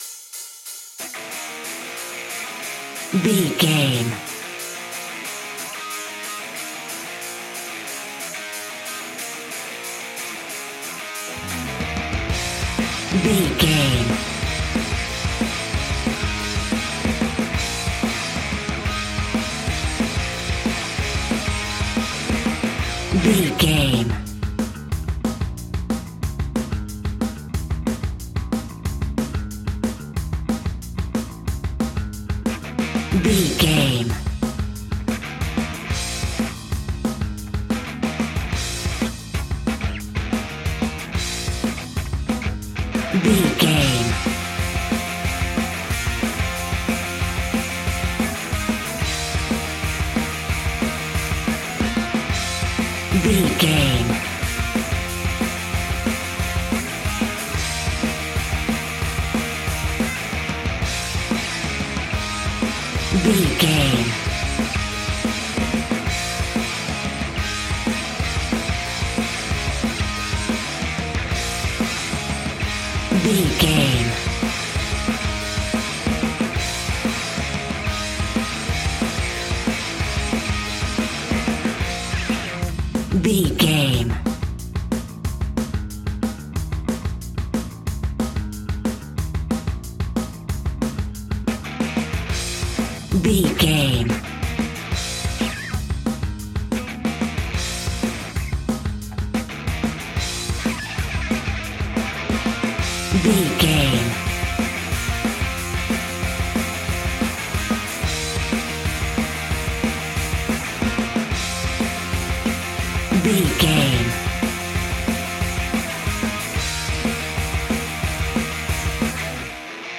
Epic / Action
Aeolian/Minor
Fast
hard rock
heavy metal
dirty rock
horror rock
rock instrumentals
Heavy Metal Guitars
Metal Drums
Heavy Bass Guitars